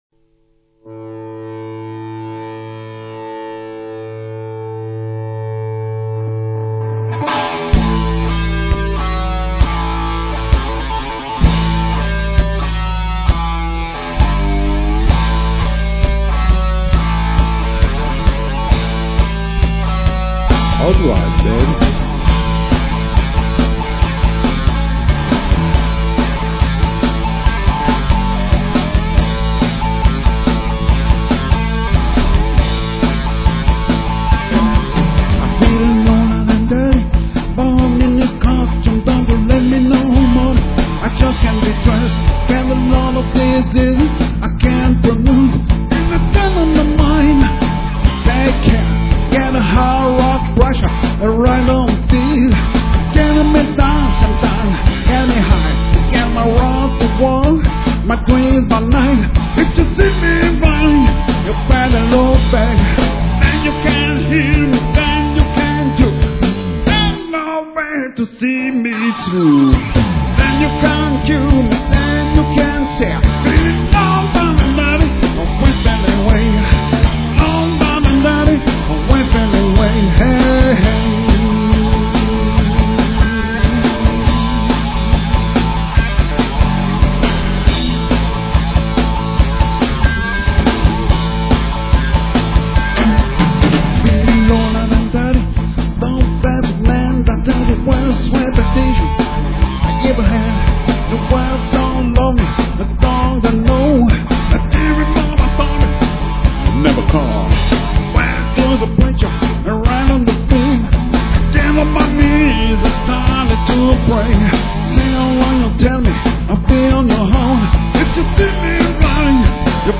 du répertoire Blues.
C'est du mp3, donc un peu compréssé,
d'ou la qualité moyenne du son…